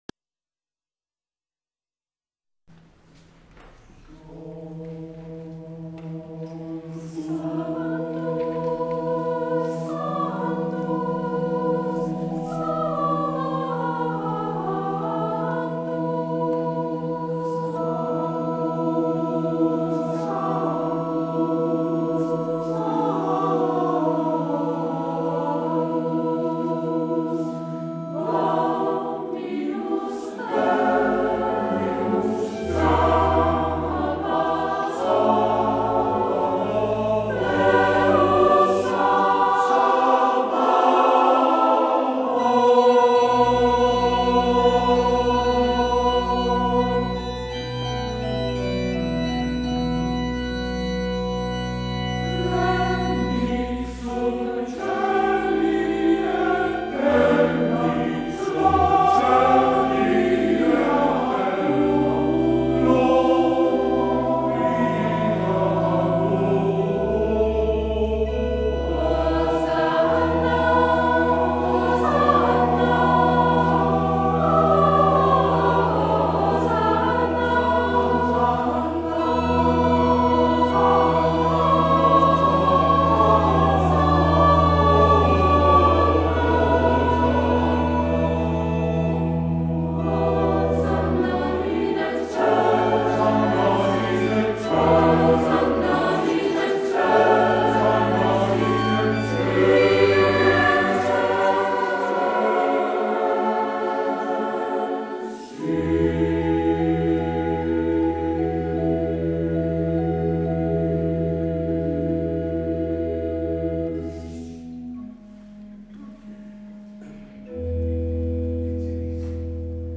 Concerto dell'Epifania
Chiesa di San Giorgio